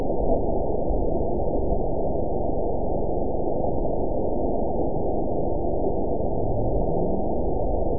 event 922683 date 03/09/25 time 18:44:38 GMT (3 months, 1 week ago) score 9.39 location TSS-AB04 detected by nrw target species NRW annotations +NRW Spectrogram: Frequency (kHz) vs. Time (s) audio not available .wav